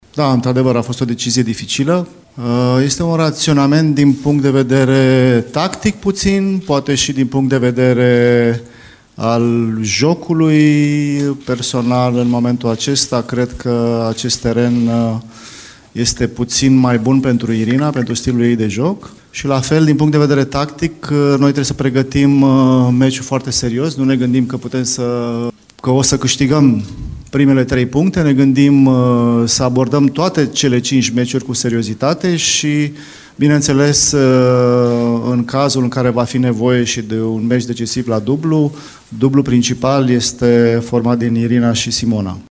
Căpitanul Florin Segărceanu a vorbit despre alegerea Irinei Begu pentru a doua rachetă de simplu.
Întâlnirea dintre România şi Elveţia, meci din play-off-ul Grupei Mondiale, este reflectată de la fața locului de postul nostru de radio.